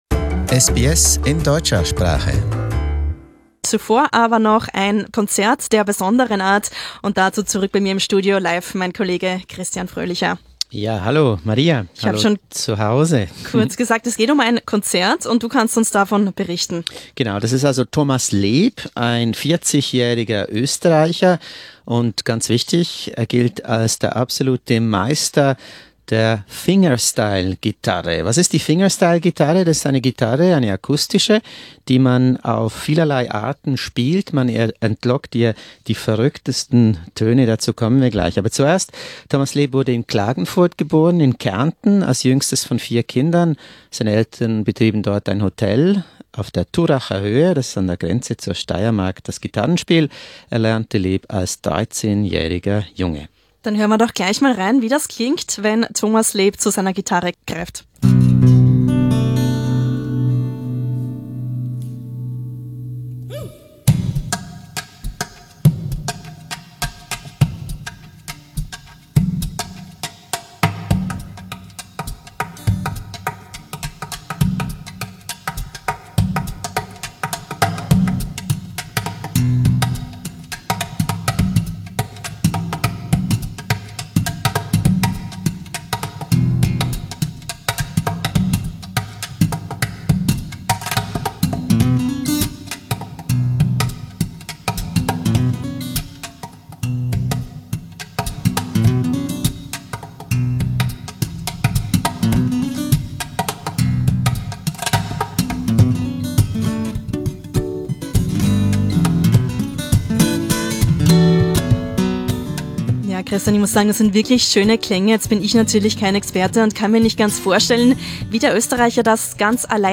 Mehr dazu, in einem Live-Gespräch bei SBS German.